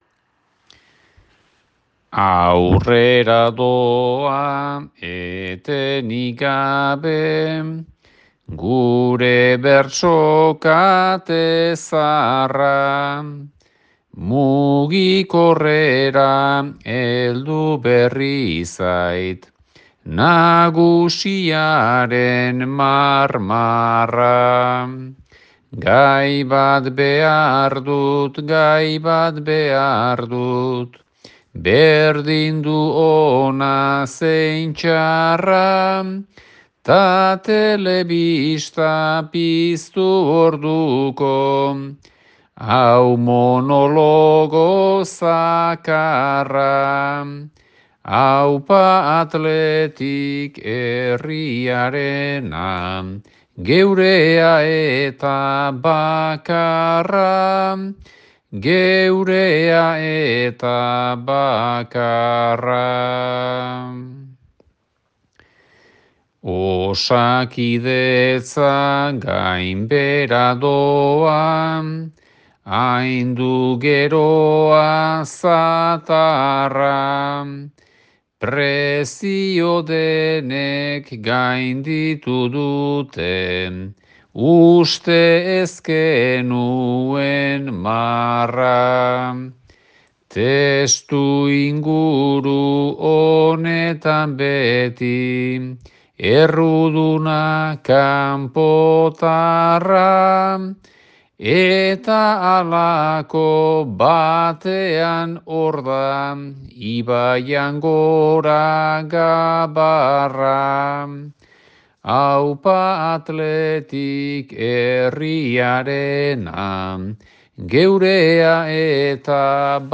bost bertsoko sorta